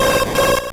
Cri de Xatu dans Pokémon Or et Argent.